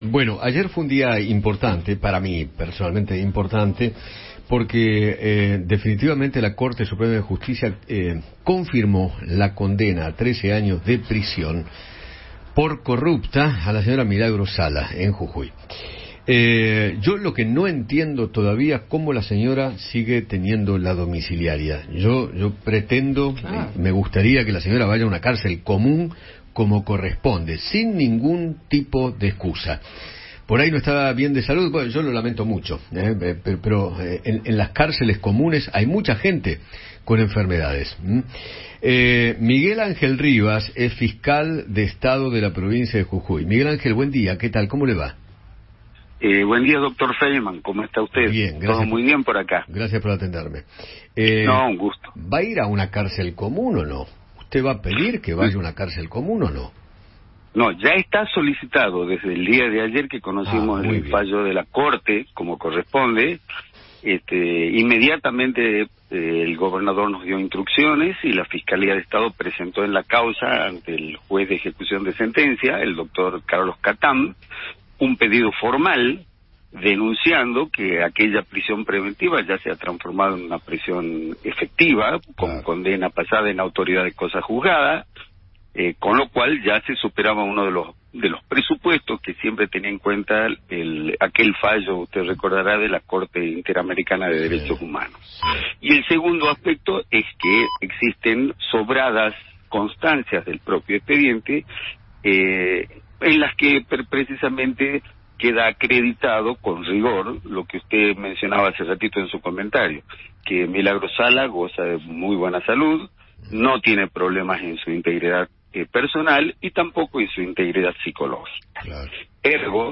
Miguel Angel Rivas, fiscal de Estado de Jujuy, conversó con Eduardo Feinmann sobre la condena a Milagro Sala a 13 años de prisión.